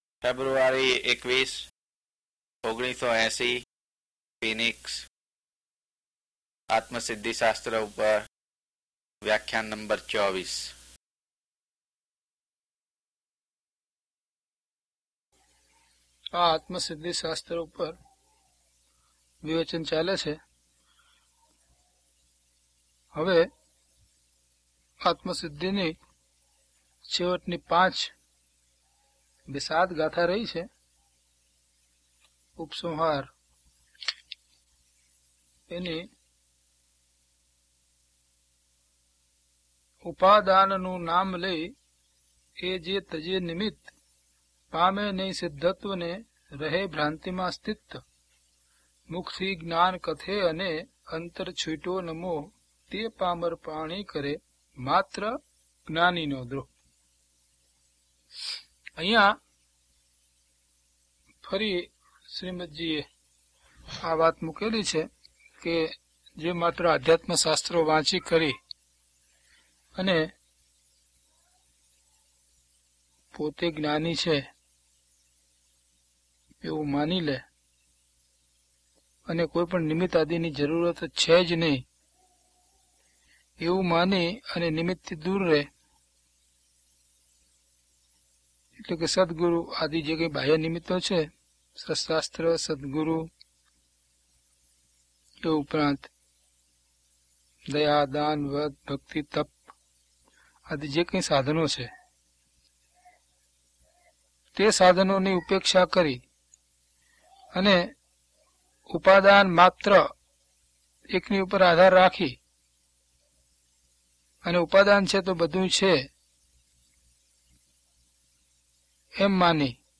DHP033 Atmasiddhi Vivechan 24 - Pravachan.mp3